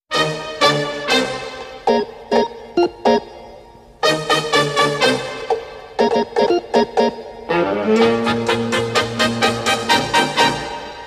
Play, download and share Bongo La 1 original sound button!!!!
bongo-la-1.mp3